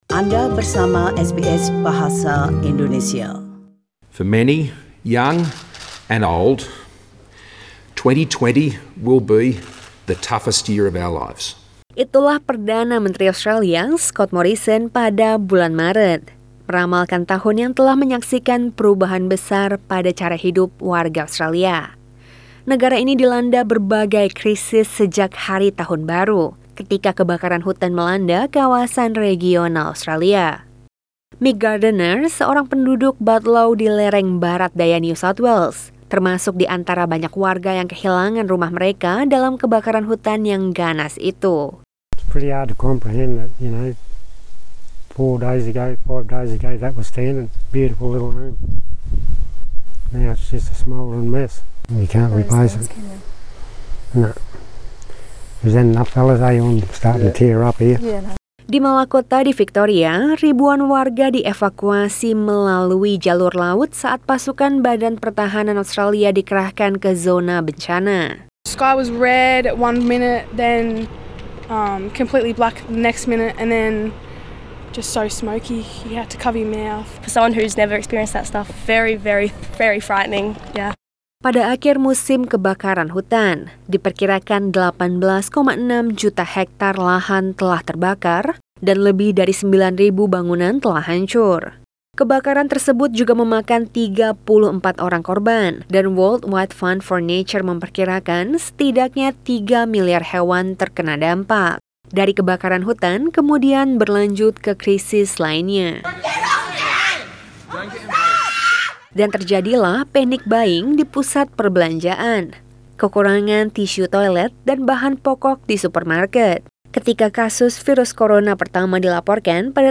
SBS Radio News in Bahasa Indonesia - 1 January 2021
Warta Berita SBS Radio dalam Bahasa Indonesia Source: SBS